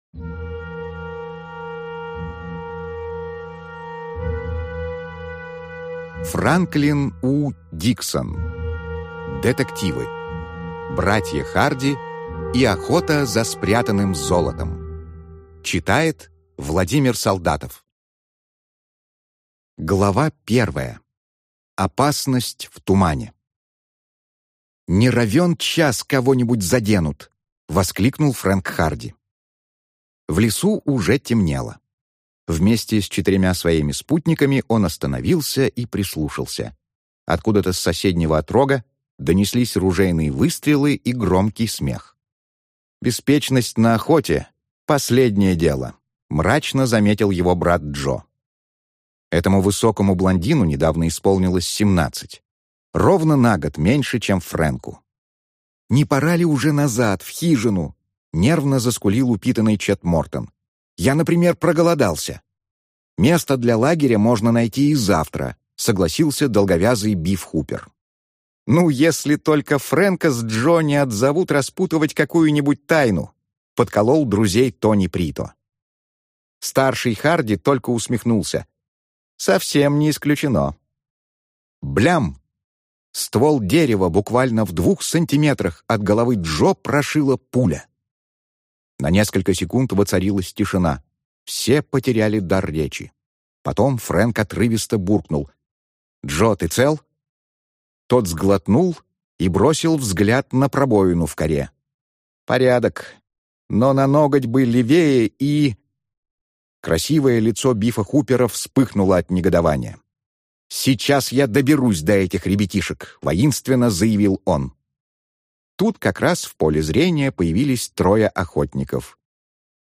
Аудиокнига Братья Харди и охота за спрятанным золотом | Библиотека аудиокниг